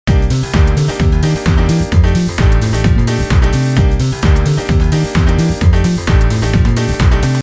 あとはダンスポップとか、四つ打ちって言われるリズムを使うジャンルによく合うんだ。
ドッチータッチードッチータッチー♪ってリズム
ほら合わせるとこんな感じになるのよ。
ノリノリ でしょ？